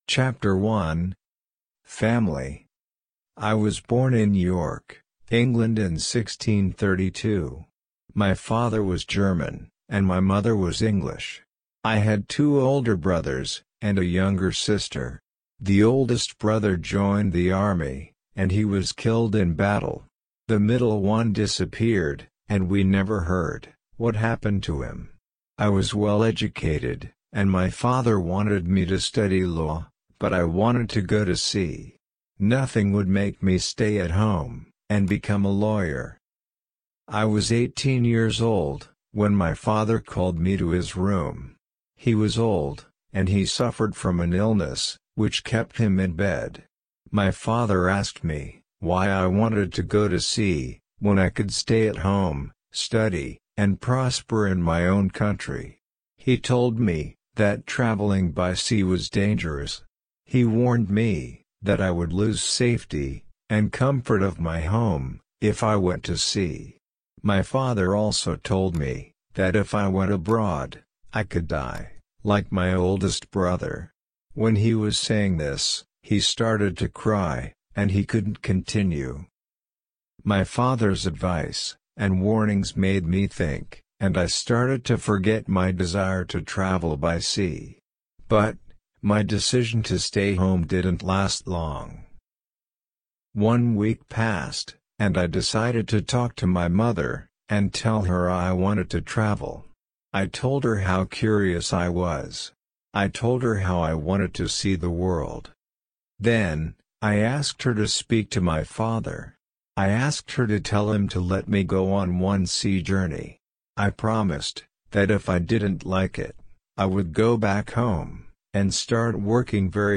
RC-L3-Ch1-slow.mp3